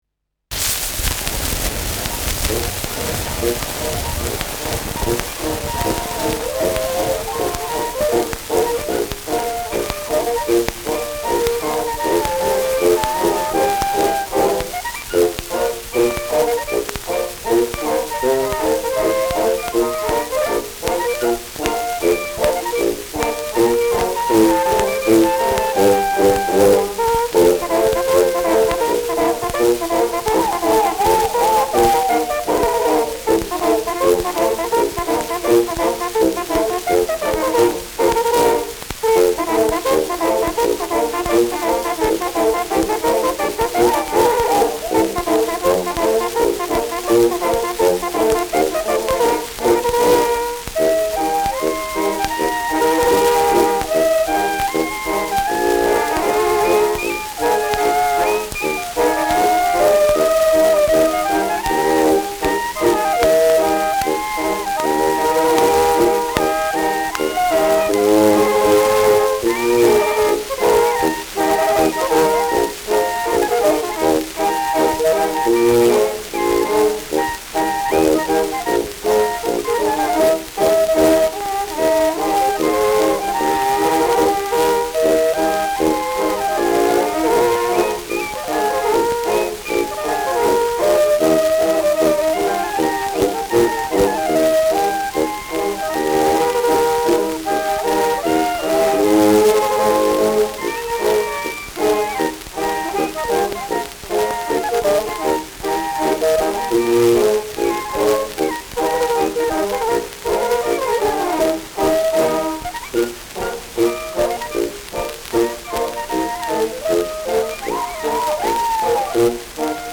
Schellackplatte
starkes Rauschen : durchgehendes Knacken : abgespielt : leiert : Knacken bei 1’04’’
Mit Juchzern.